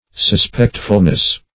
Meaning of suspectfulness. suspectfulness synonyms, pronunciation, spelling and more from Free Dictionary.